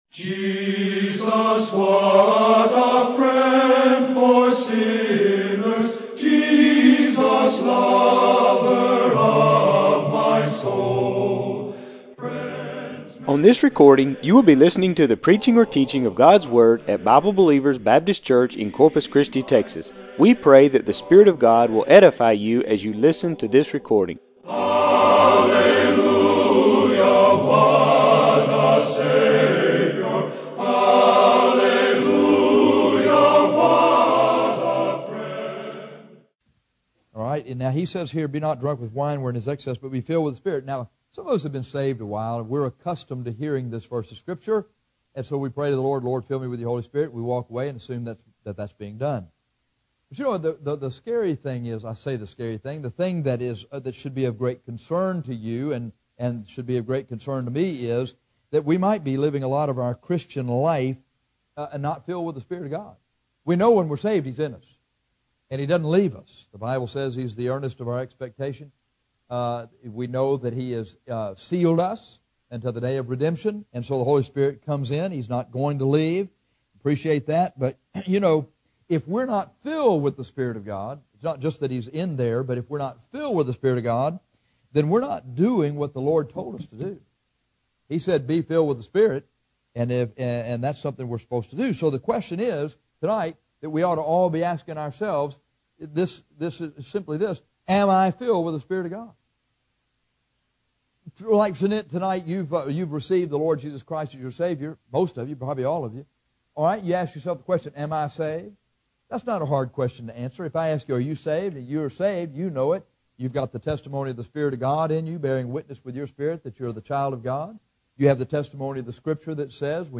Here is a simple sermon to help you determine whether you are truly filled with the Spirit.